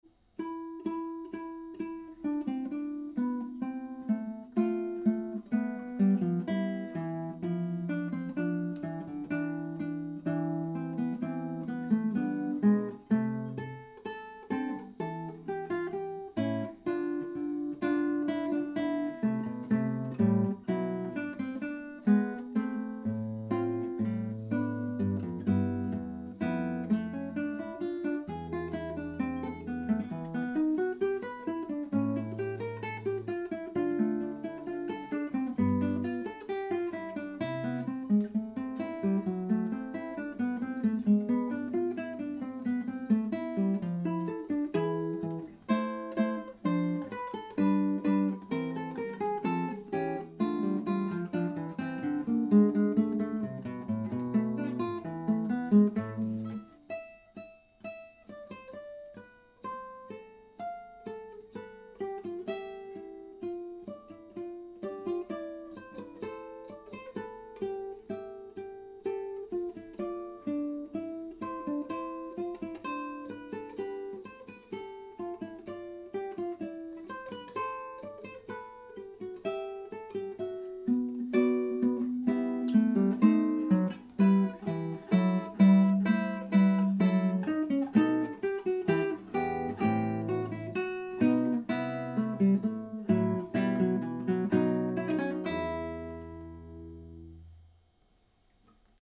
Perhaps the attached clip will give you some idea of the mic's quality? Not opera but a 'natural' acoustic instrument, a decent, £2000 'concert' classical guitar (new strings he tells me!)
Attachments USB MIC Fugue in 27 8 200Gminor(01.mp3 USB MIC Fugue in 27 8 200Gminor(01.mp3 3.8 MB · Views: 140